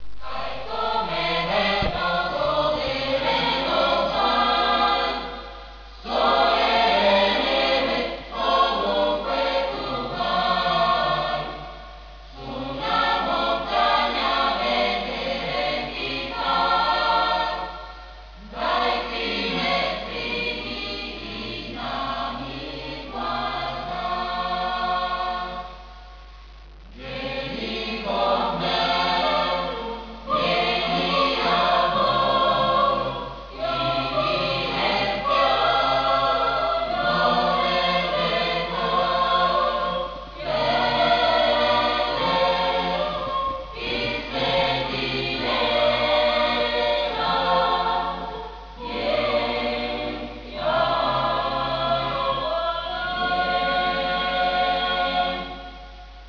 Text: Leopold Arnold
Musik: Leo Weiter
wav file of z'Wallis im Winter (per sentire il canto corale) (to hear the song)